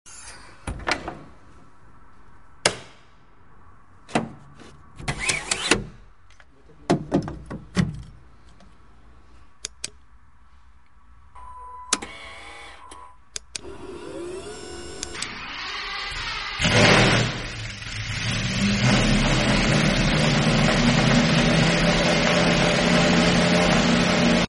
( $2.2M ) ASMR 1 sound effects free download
The Lamborghini Essenza SCV12 is a limited-production track-focused hypercar created by the Italian automaker Lamborghini. Powered by a naturally aspirated V12 engine producing over 800 horsepower, it accelerates from 0 to 100 km/h in approximately 2.8 seconds, with a top speed exceeding 320 km/h. This hypercar features a carbon fiber chassis, aggressive aerodynamics, and a unique design that prioritizes performance and aerodynamic efficiency.